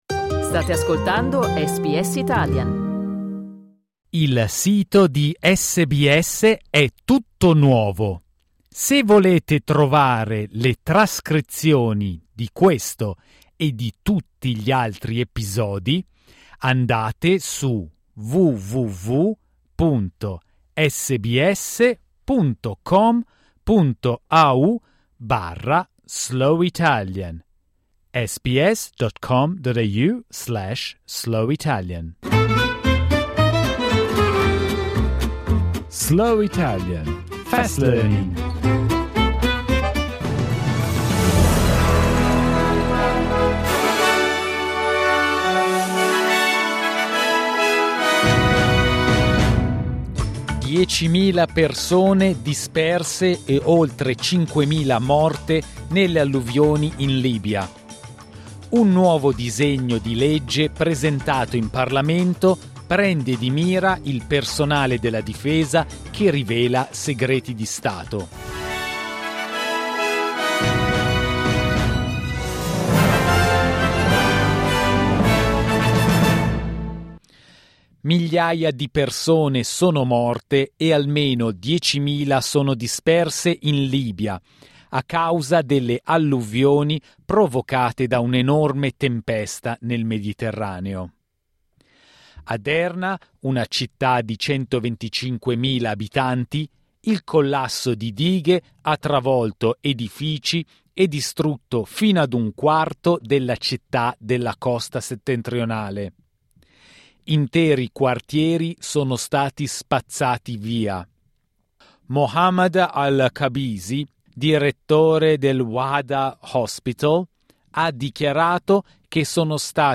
Il giornale radio di SBS, letto lentamente.